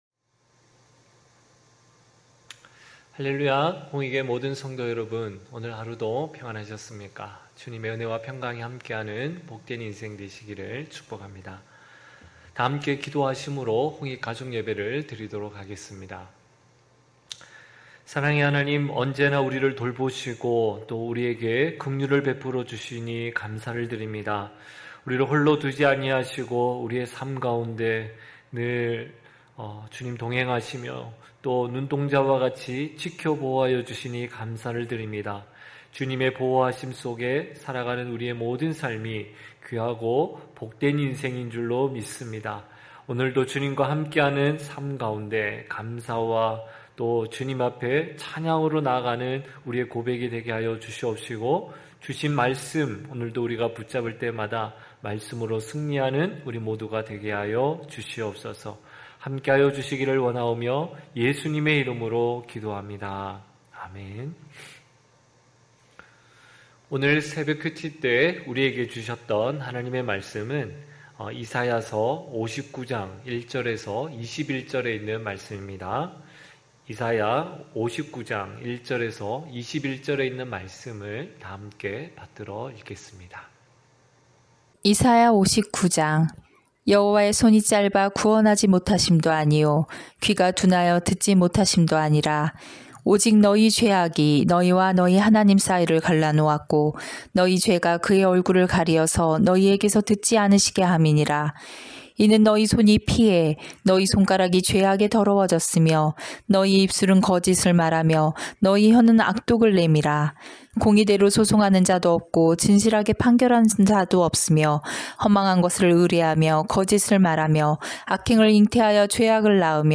9시홍익가족예배(8월8일).mp3